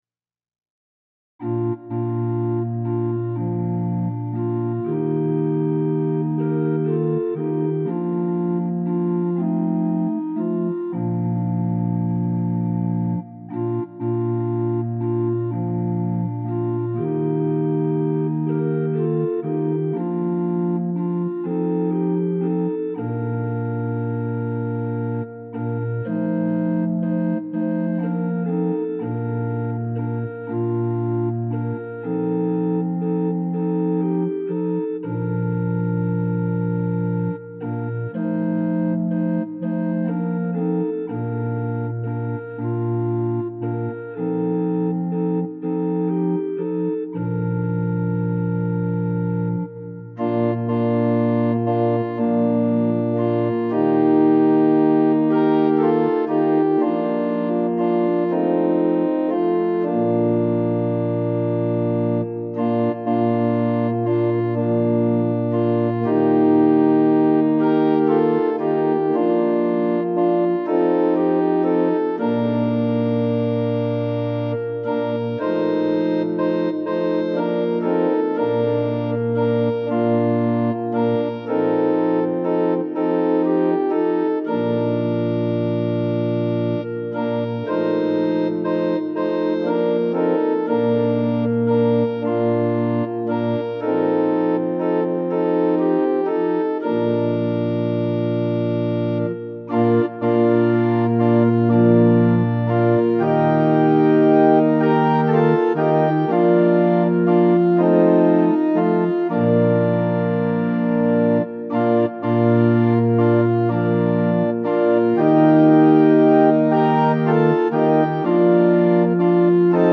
♪賛美用オルガン伴奏音源：
・柔らかい音色は前奏です
・はっきりした音色になったら歌い始めます
・節により音色が変わる場合があります
・間奏は含まれていません
Tonality = B (B♭)
Pitch = 440
Temperament = Equal